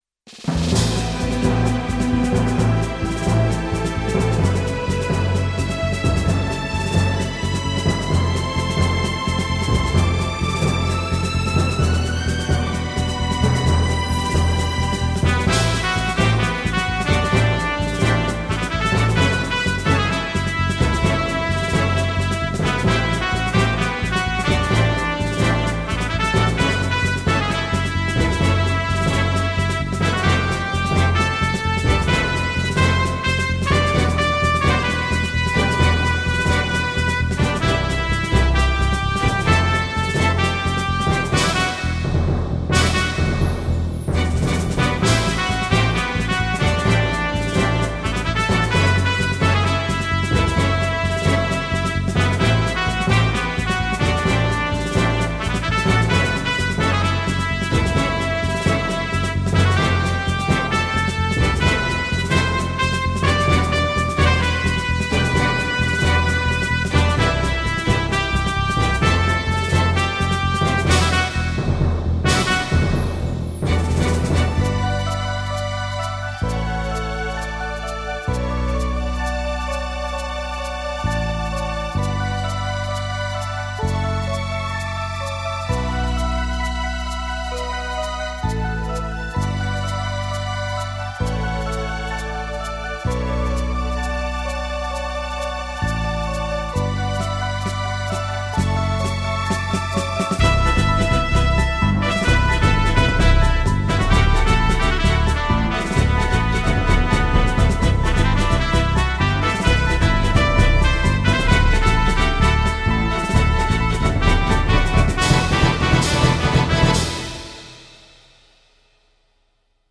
エレクトーンでゲーム音楽を弾きちらすコーナー。